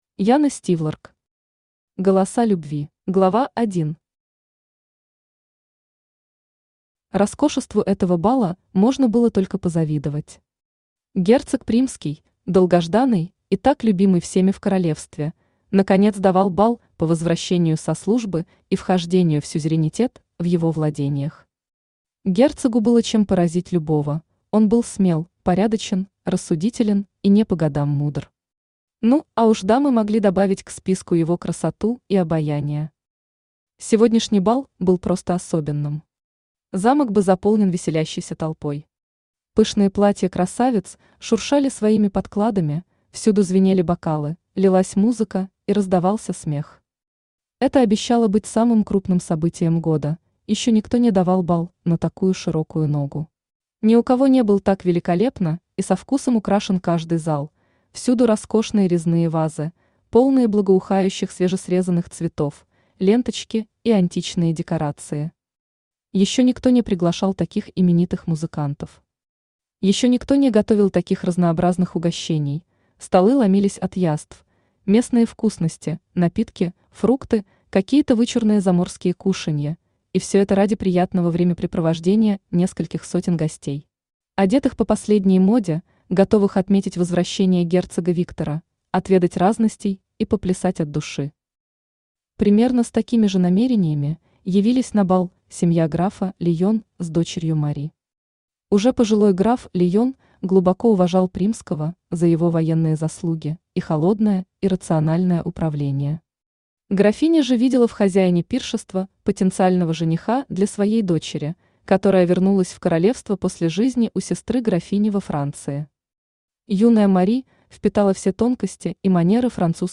Аудиокнига Голоса Любви | Библиотека аудиокниг
Aудиокнига Голоса Любви Автор Яна Стивлорк Читает аудиокнигу Авточтец ЛитРес.